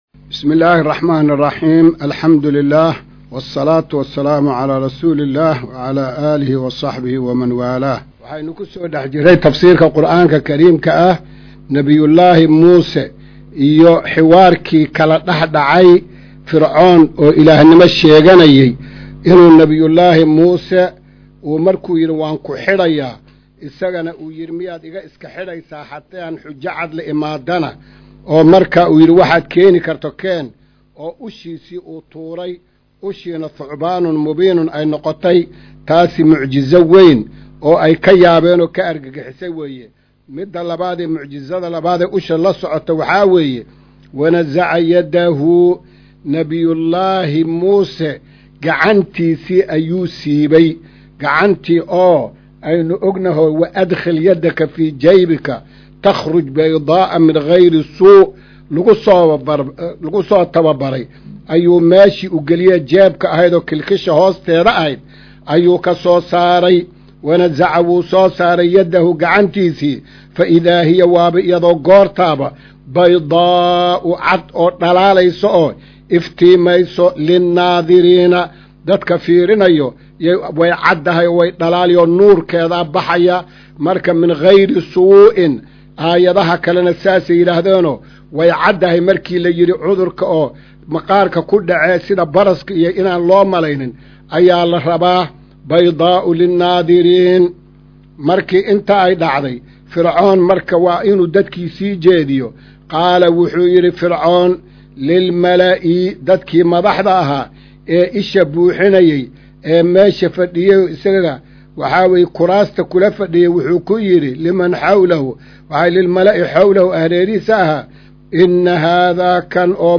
Maqal:- Casharka Tafsiirka Qur’aanka Idaacadda Himilo “Darsiga 178aad”
Casharka-178aad-ee-Tafsiirka.mp3